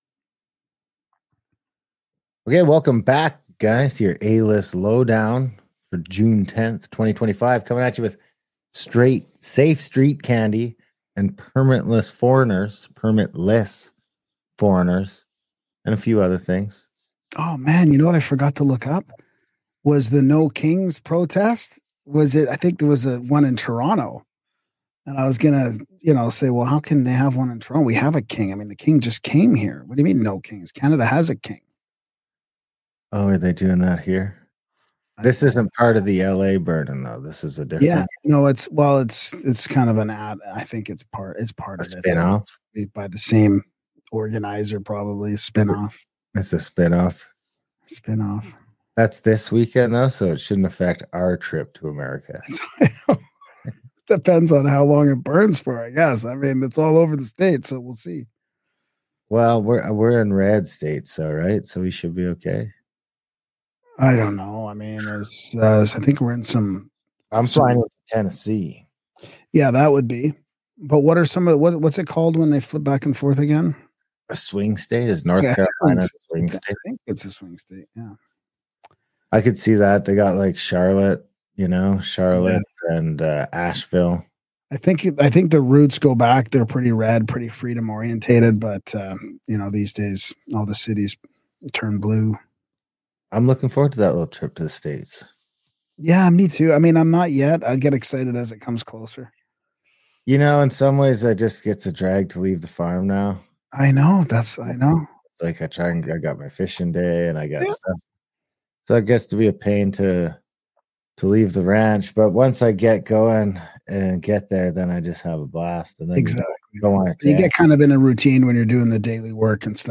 Your Canadian News deconstruction with zero ads.